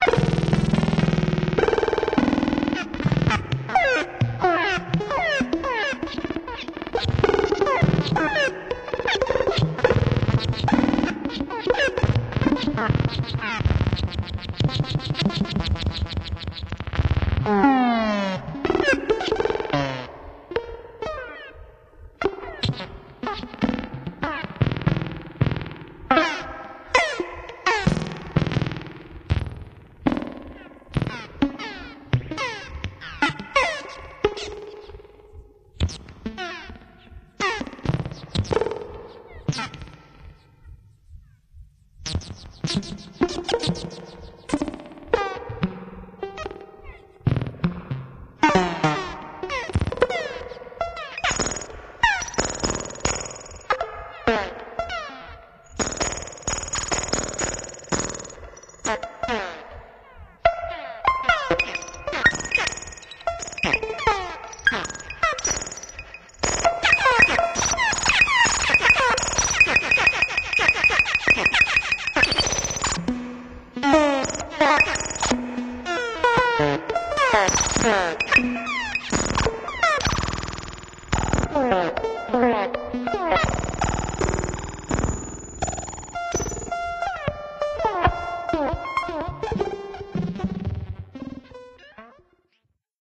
The complete set of my modified Synthi A TKS